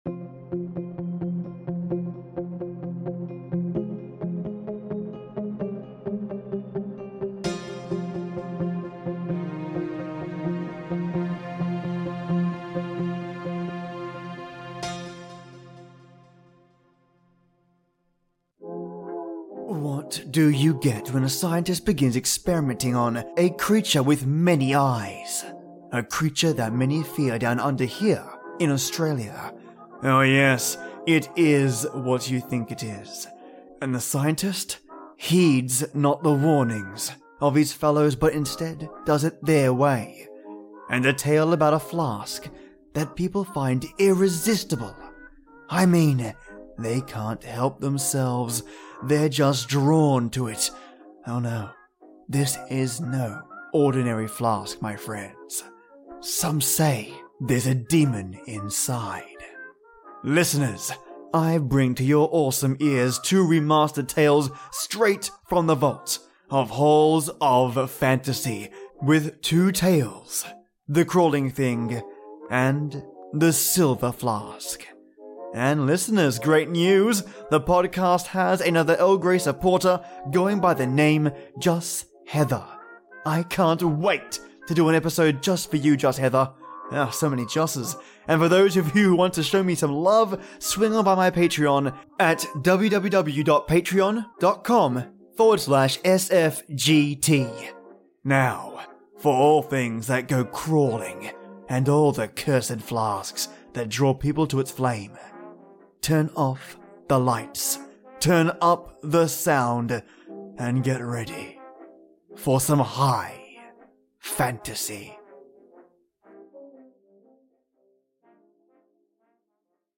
A monstrous creature being grown in a lab take out it's "growing pains" on the scientists that helped create it, and a corrupt cursed, silver flask has the power to beguile and persuade those to do it's cruel and evil bidding. Join me for more tales from the Old Time Radio show "Halls of Fantasy" with two tales - The Thing that Crawls, and The Silver Flask.